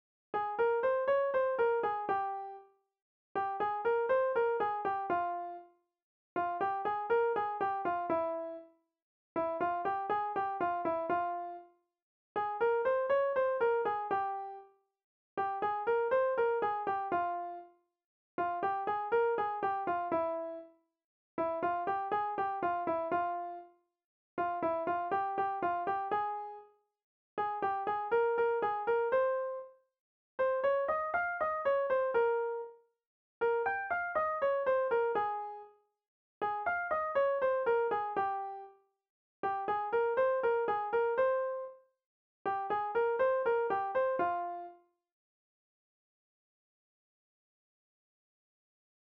Kontakizunezkoa
8 / 8A / 8 / 8A / 8 / 8A / 8 / 8A / 8 / 8A / 8 / 8A / 8 / 8A